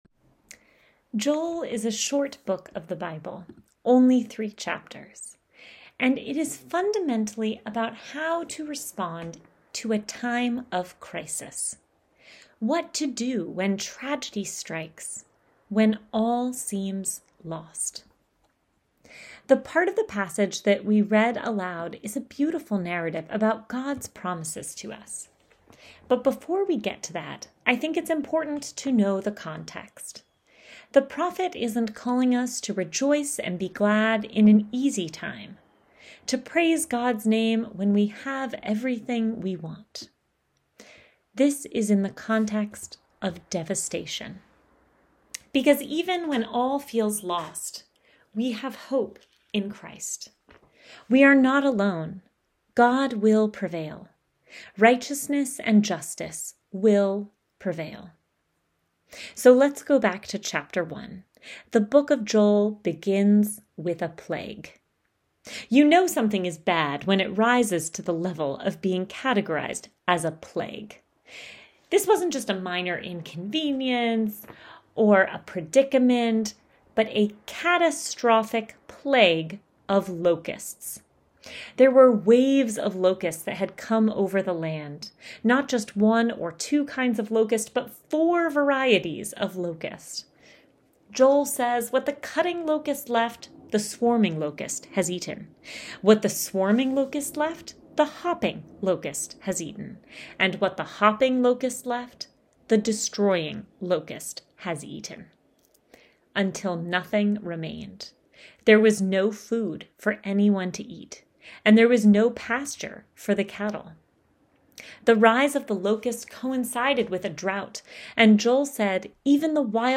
Sermon Podcast | Church of St. James the Less